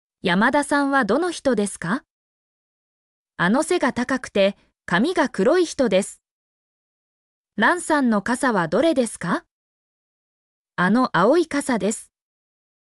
mp3-output-ttsfreedotcom-64_tzUODCbP.mp3